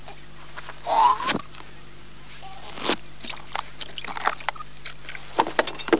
home *** CD-ROM | disk | FTP | other *** search / Horror Sensation / HORROR.iso / sounds / iff / grouse1.snd ( .mp3 ) < prev next > Amiga 8-bit Sampled Voice | 1992-12-21 | 120KB | 1 channel | 19,886 sample rate | 6 seconds
grouse1.mp3